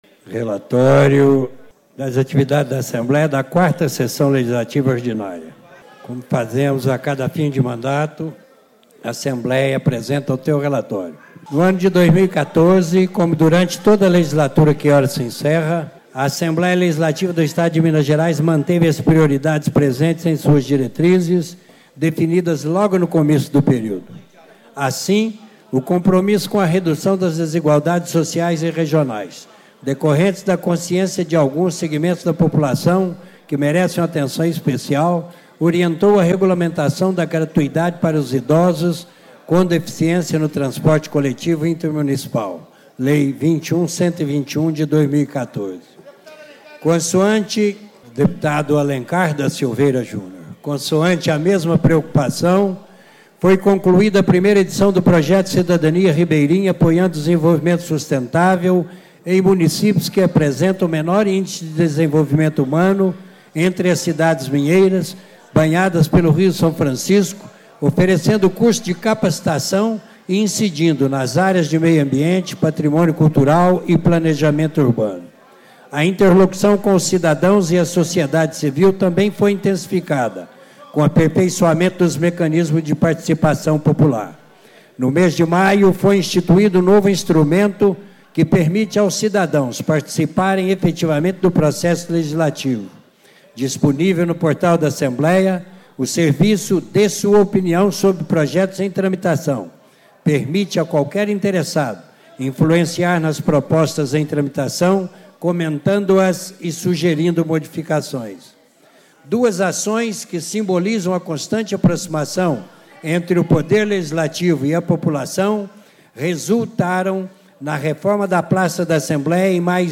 Deputado Dilzon Melo, PTB - 1º Secretário da Mesa da Assembleia
Reunião Ordinária de Plenário - Leitura do Relatório de Atividades Institucionais da ALMG em 2014